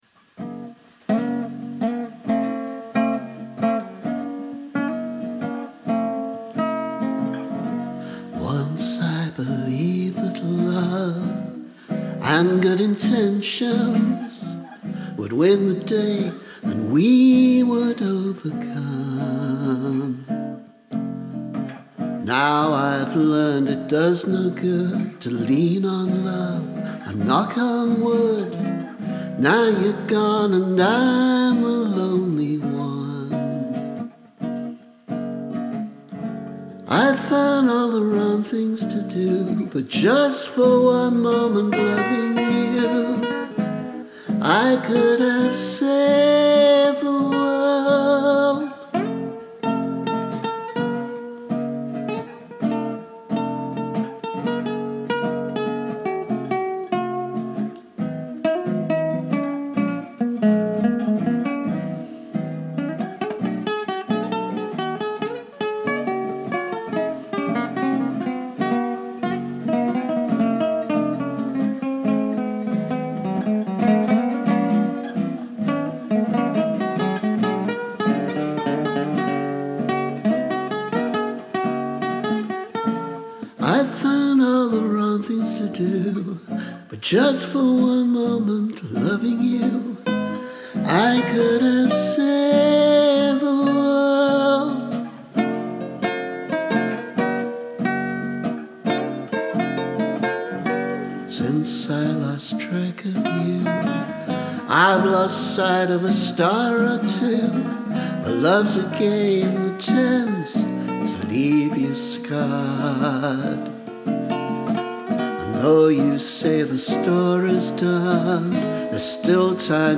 A song I wrote in the early 70s. The final version probably won’t be much different to this, just a bit tidier. Especially the vocal and the abrupt entry of the lead guitars…
Vocal and Spanish guitars